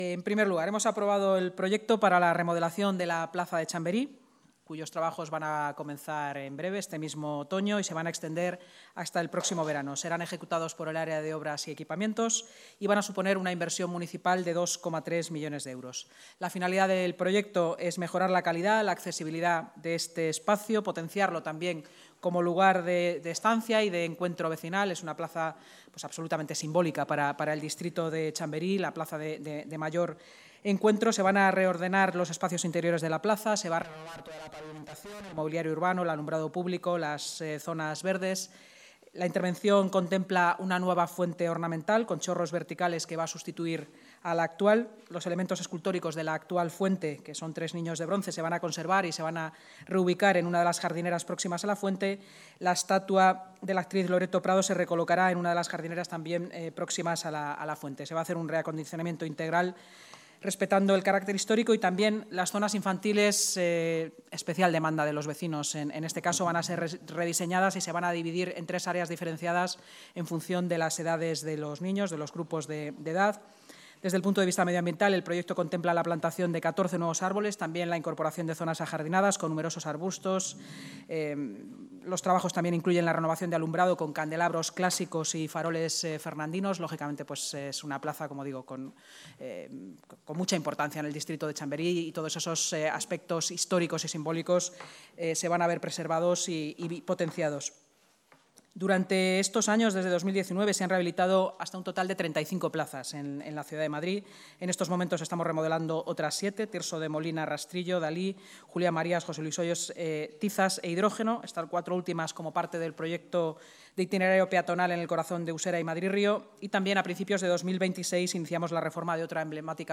Así lo ha anunciado la vicealcaldesa y portavoz municipal, Inma Sanz, tras la Junta de Gobierno.
Rueda de prensa posterior a la Junta de Gobierno